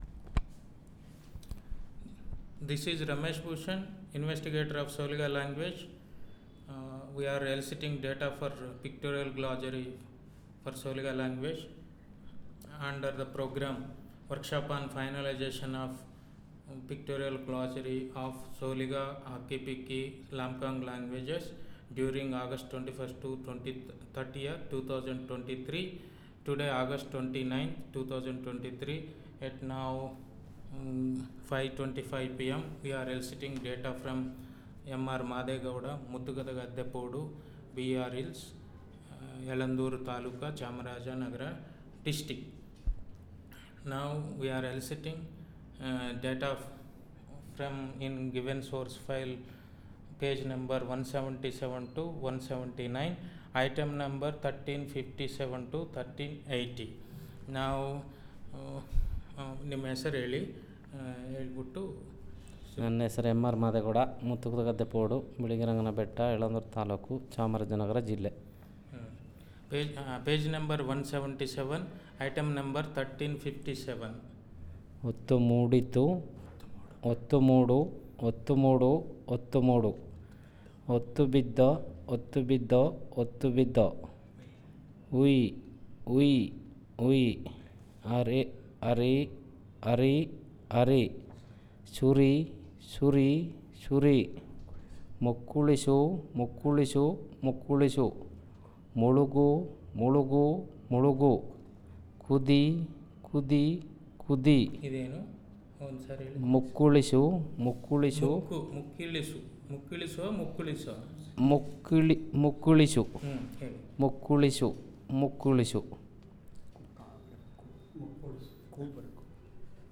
Elicitation of wordlist on multidomain words; Nouns, Verbs, Adverbs, Adjectives
NotesThis is an elicitation of wordlist on Multidomain related (Nouns, Verbs, adverbs, adjectives) by using pictures in pictorial glossary 1357-1380 items (Pages 177-179).